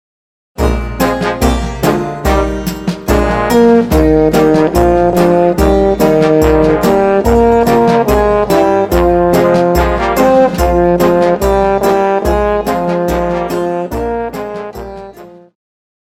Classical
French Horn
Band
Traditional (Folk),Classical Music,Classical Rearrangement
Instrumental
Only backing